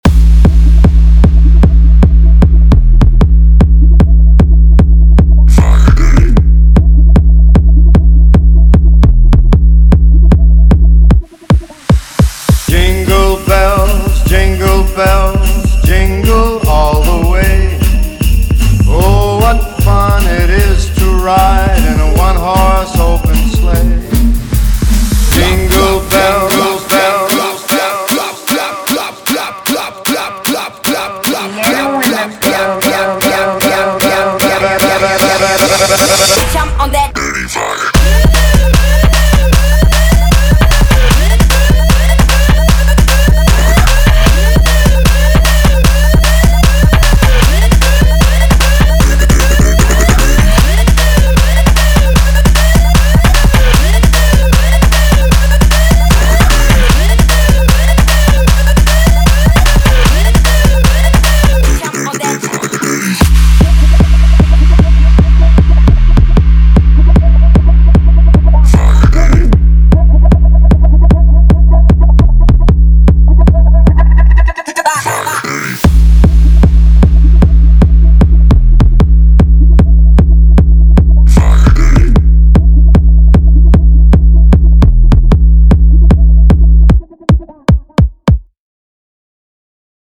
Category: Latest Dj Remix Song